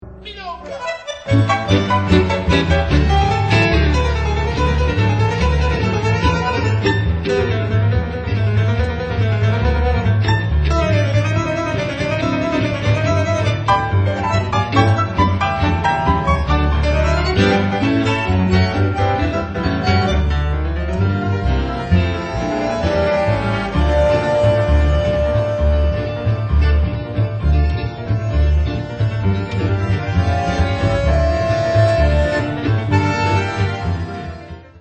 Einige gern gehörte/ getanzte Milonga-Stücke